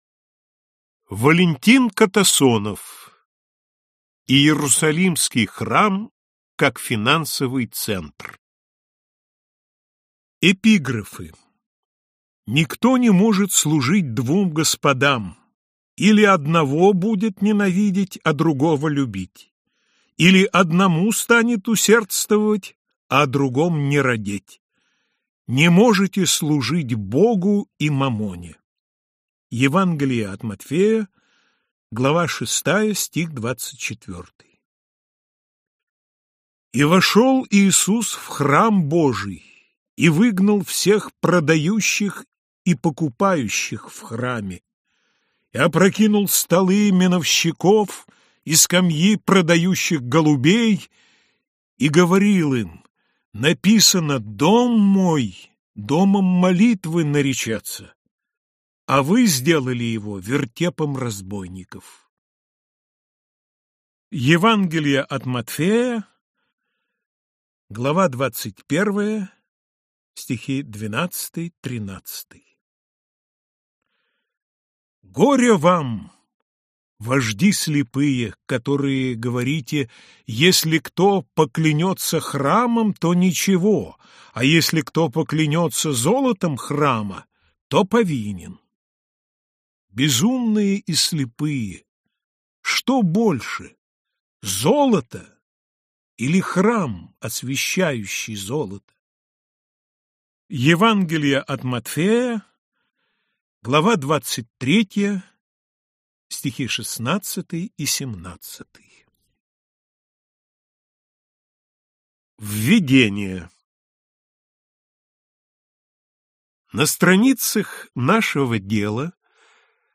Аудиокнига Иерусалимский храм как финансовый центр | Библиотека аудиокниг